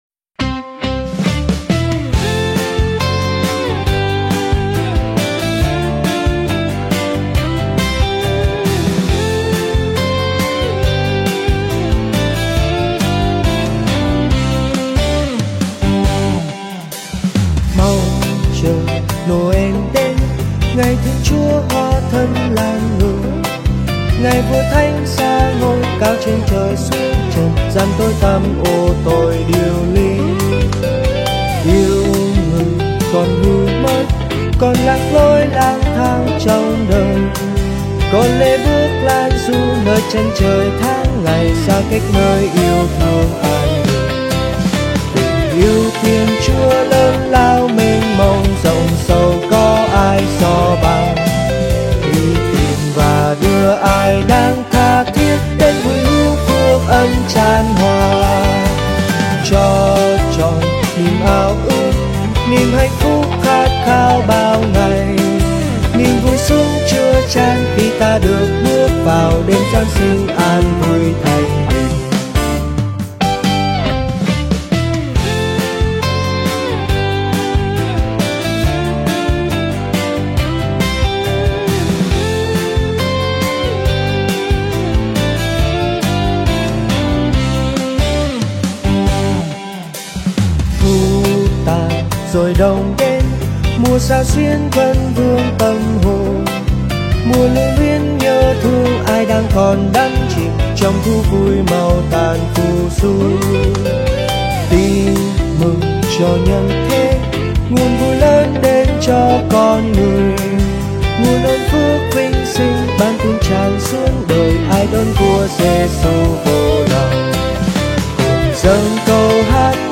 Nhạc Thánh